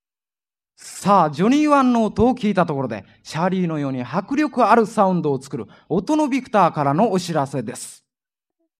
1976年2月、高田馬場BIGBOXで開催されたDJ大会。
▶ DJ音声④（CM）
④DJの声-日本ビクターCM始まり-4.mp3